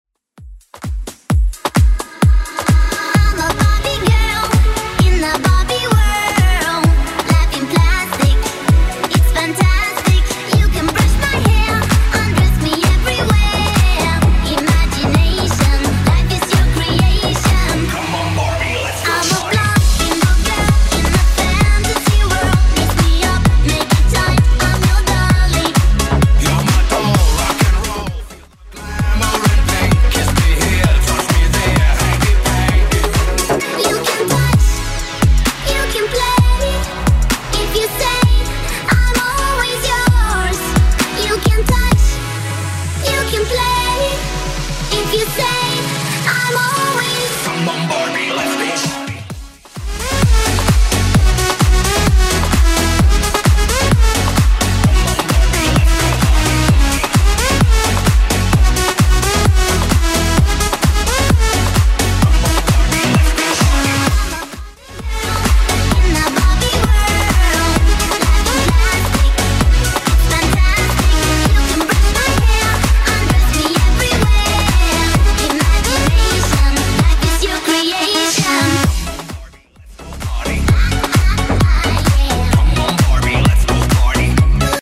Genre: Version: BPM: 130 Time: 2:57